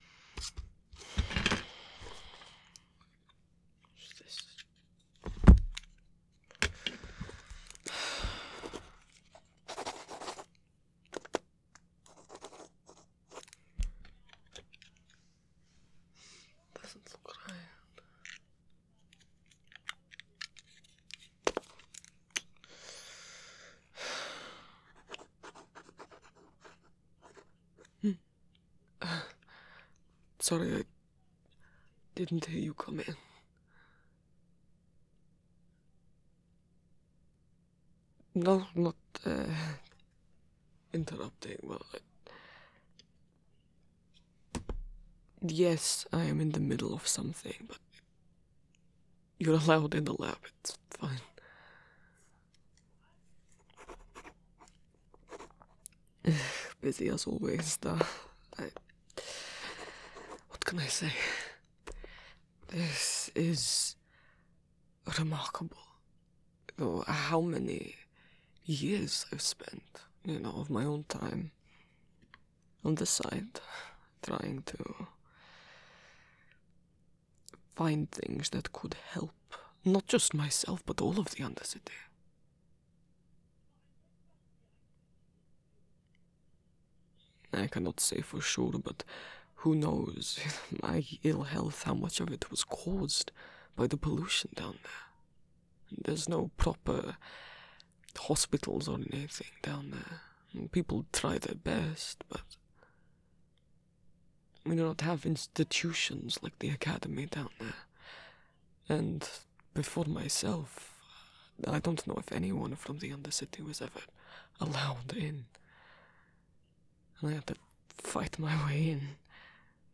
[M4A] [Season 1 Act 1] [Pre-Getting together romantically] [Touch starved Viktor] [Reverse comfort] [Cuddles] [Sleep aid] [Purposely left ambiguous so you can imagine yourself or Jayce as the listener] [Implied romantic feelings at the end]